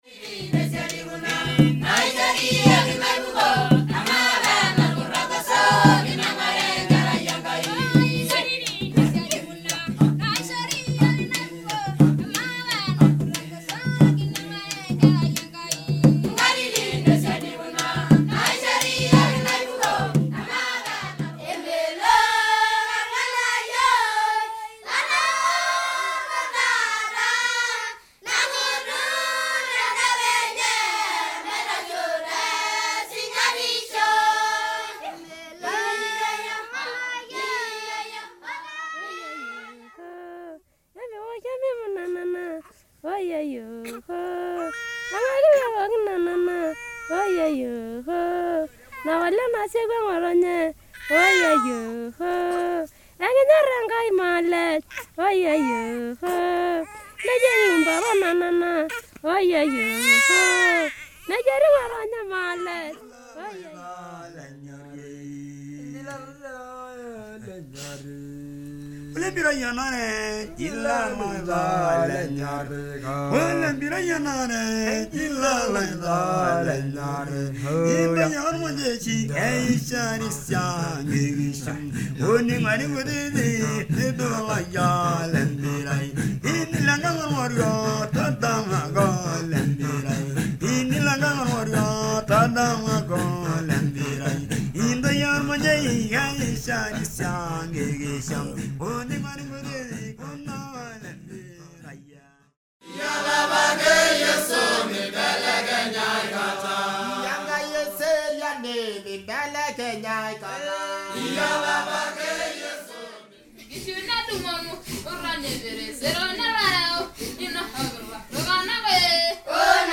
3D spatial surround sound "African tribe"
3D Spatial Sounds